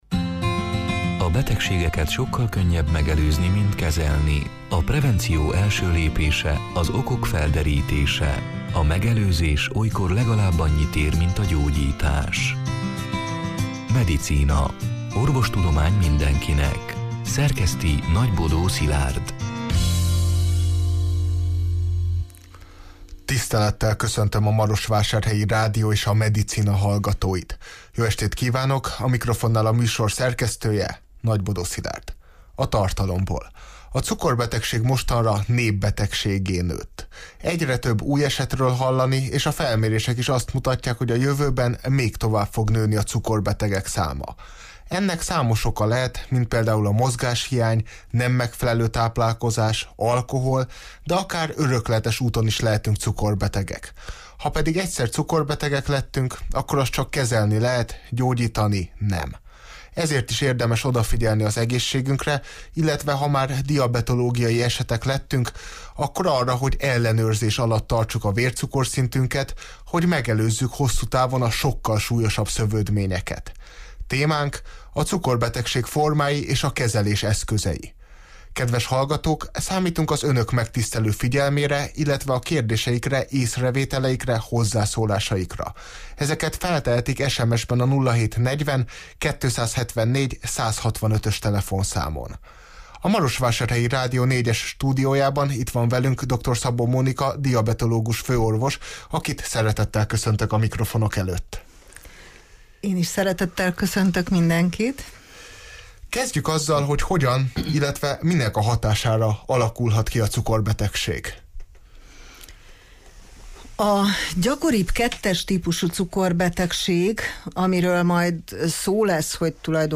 A Marosvásárhelyi Rádió Medicina (2022. szeptember 21-én, szerdán 20 órától élőben) c. műsorának hanganyaga: